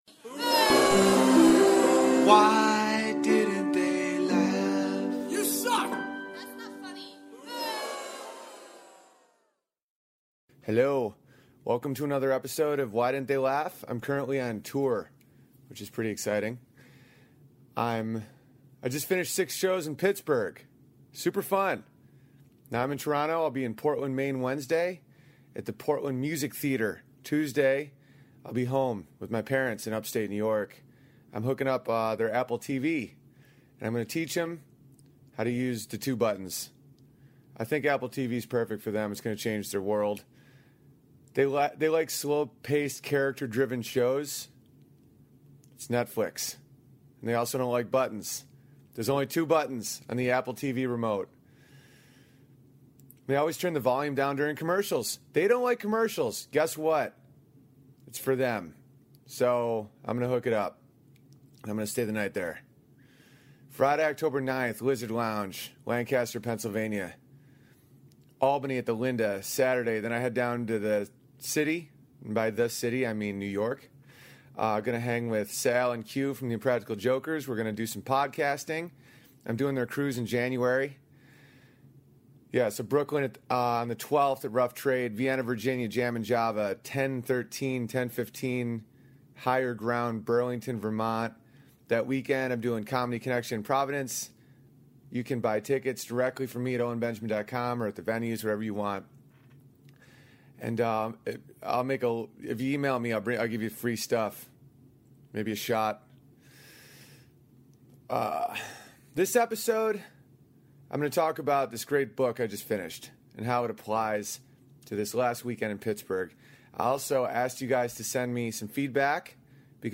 This episode I discuss a great book "thanks for the feedback" by Douglas Stone and Sheila Heen and show feedback loops and reactions from a show I just did at the Improv in Pittsburgh. Our self image and emotions greatly distort the world around us.